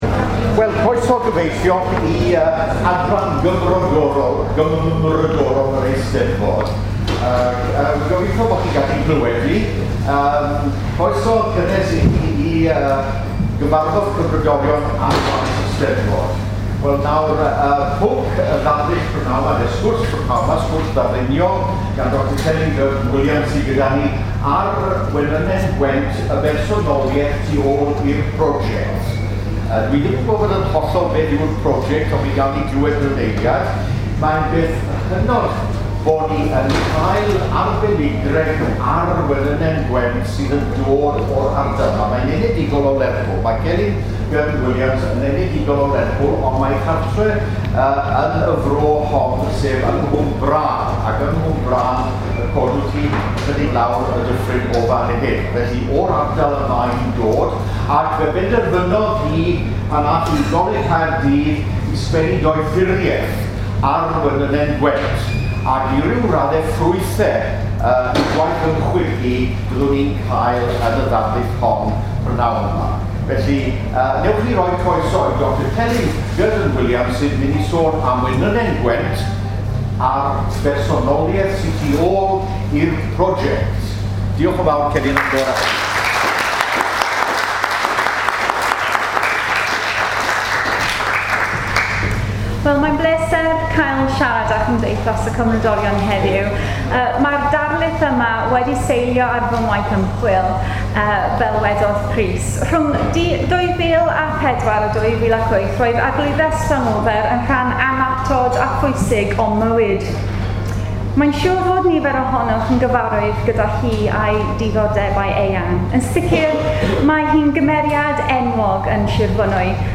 The Society’s Eisteddfod Lecture
The National Eisteddfod, Abergafenni, Pabell y Cymdeithasau 1